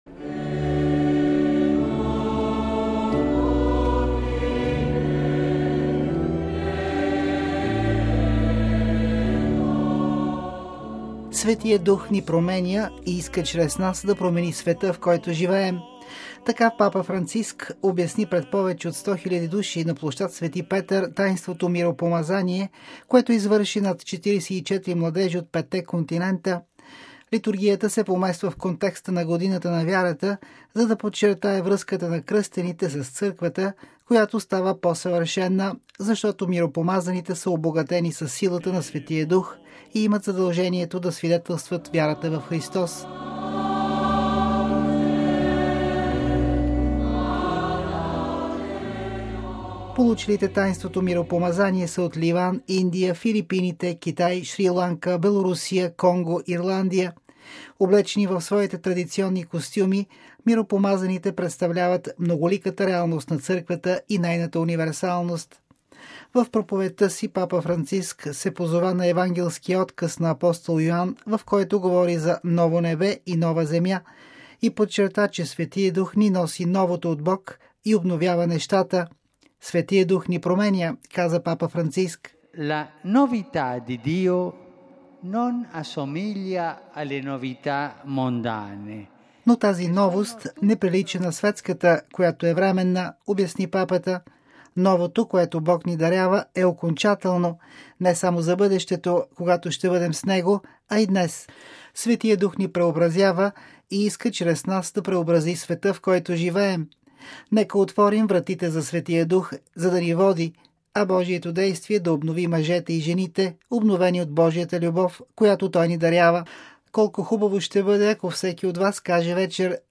На препълнения площад Свети Петър присъстват и много младежи, които заедно с миропомазаните Папата насърчи „да имат непоколебима вяра и надежда в Господ”: